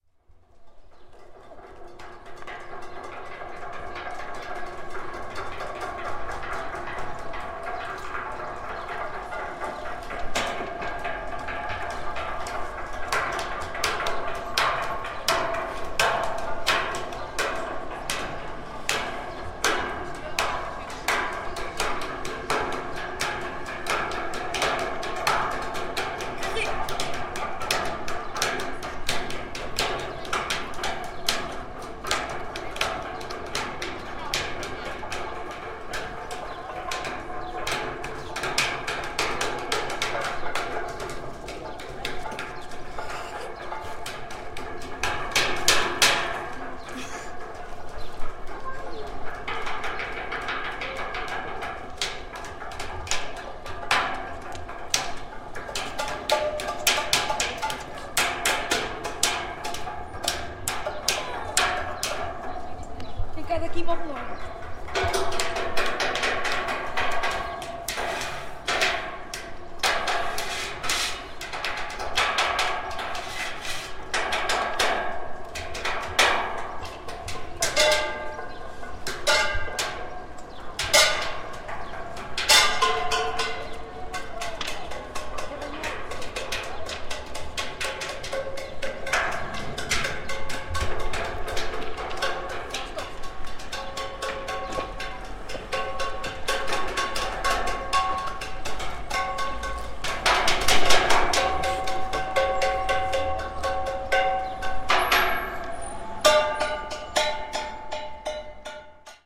Gravação de uma improvisação que surgiu nas escadas do elevador. Gravado com FR-2LE e um microfone Tellinga.
Tipo de Prática: Arte Sonora
Viseu-Largo-de-Santa-Cristina-Improvisação-nas-escadas-do-elevador.mp3